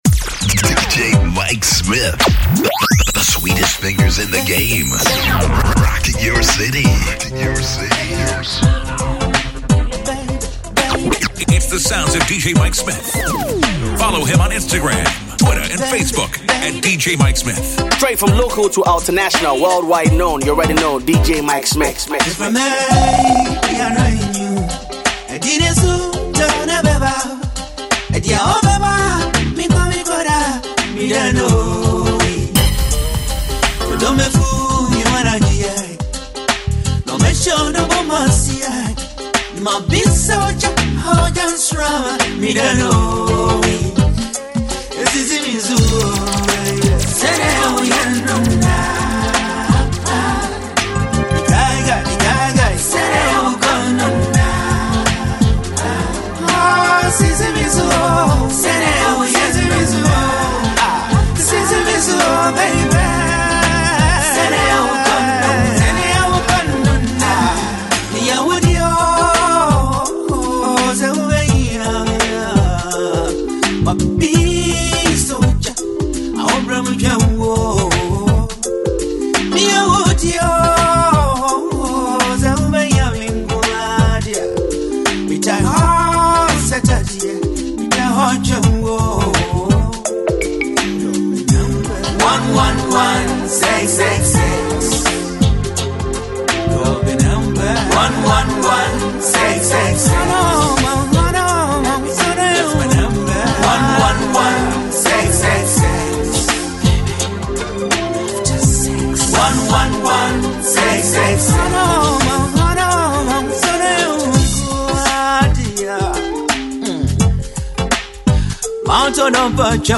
Enjoy this 51-minute-long mixtape for your ears only.